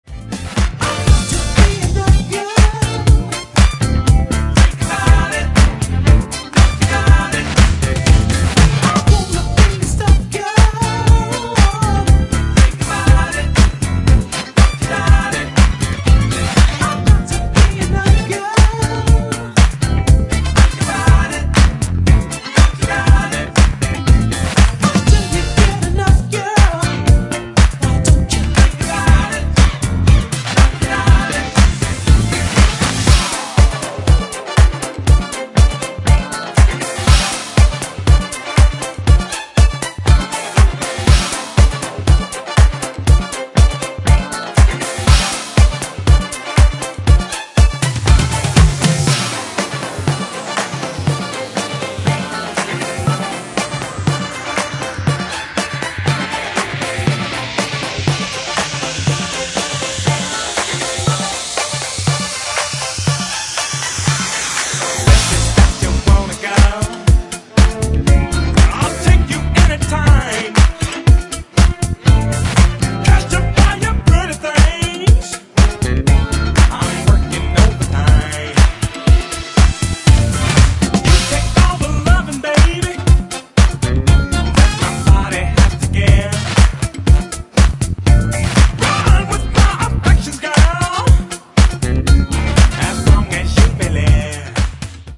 ブギーなディスコ・ハウスで盛り上がり間違いありません！！
ジャンル(スタイル) DISCO HOUSE / RE-EDIT